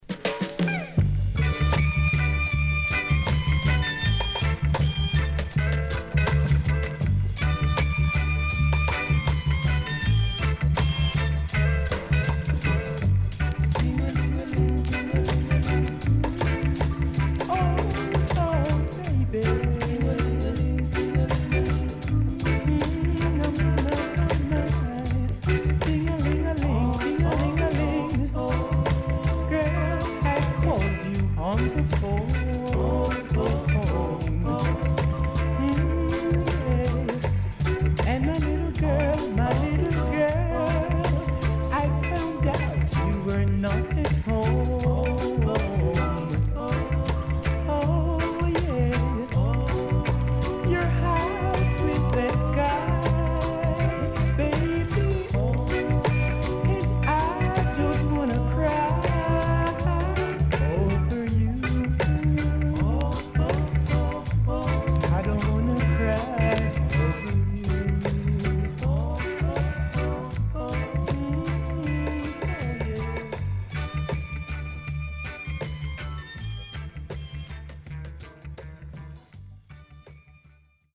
SIDE A:プレス起因も含め全体的にチリノイズがあり、所々プチパチノイズ入ります。